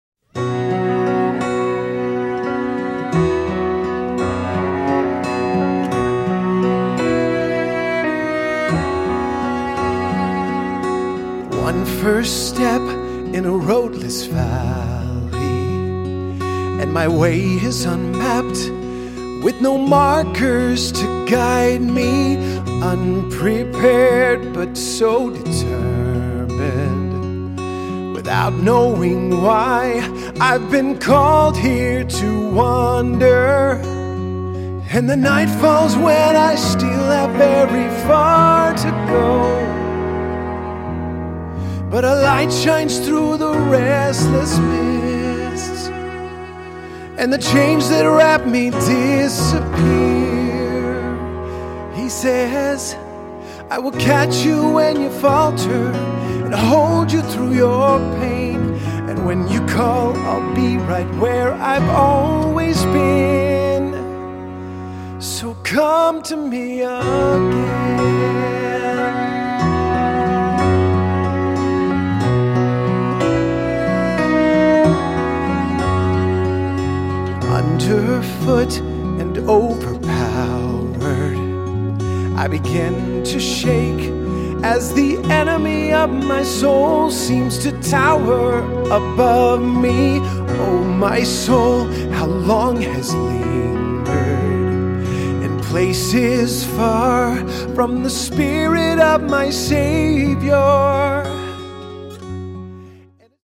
fun rhythm